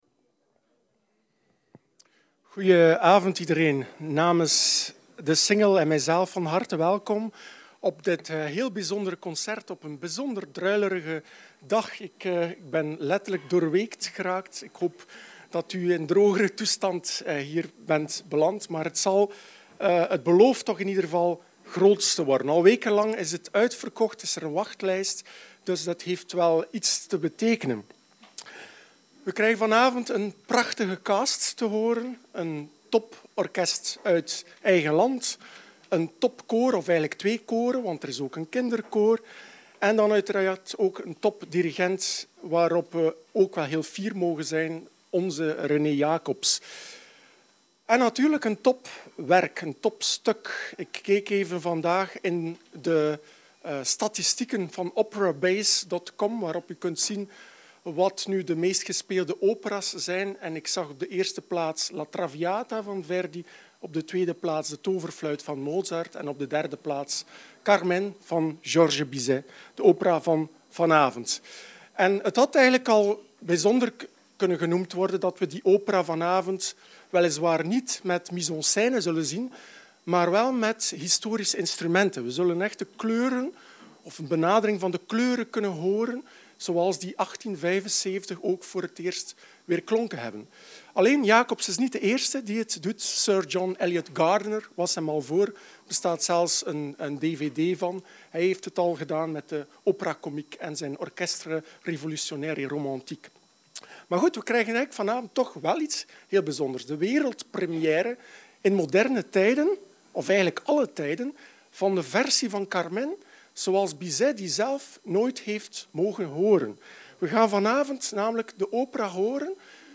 Introduction to a concert